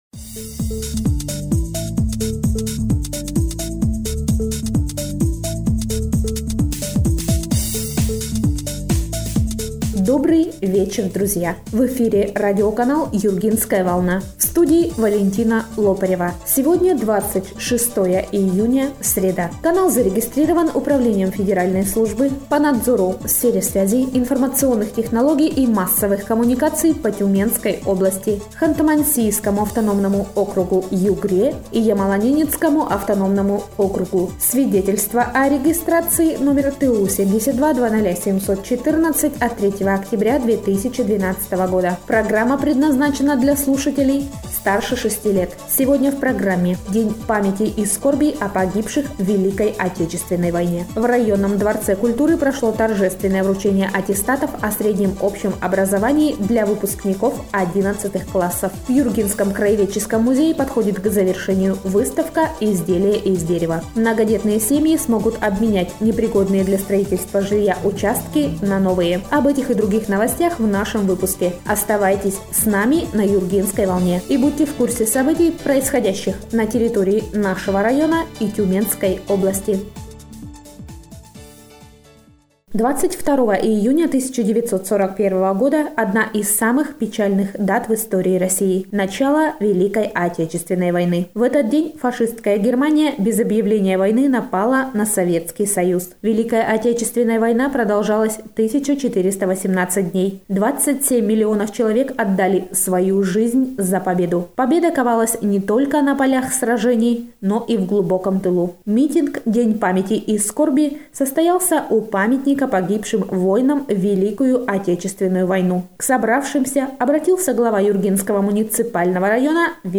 Эфир радиопрограммы "Юргинская волна" от 26 июня 2019 года